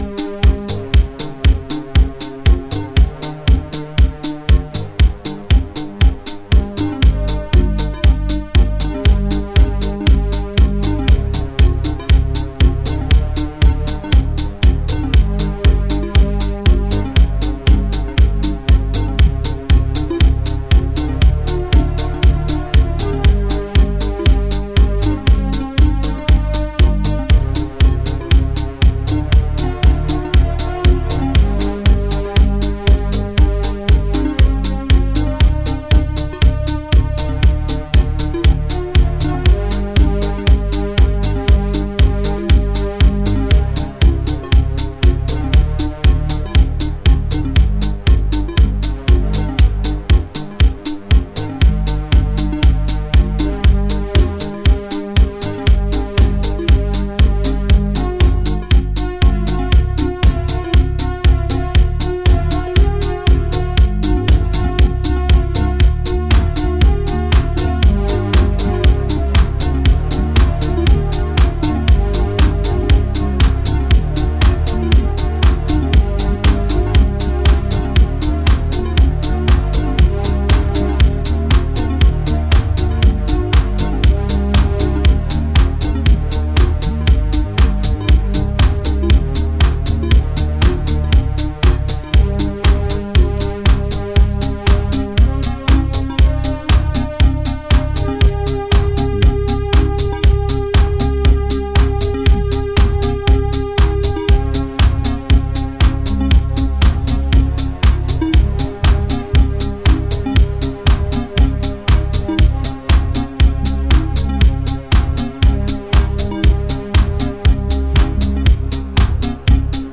Chicago Oldschool / CDH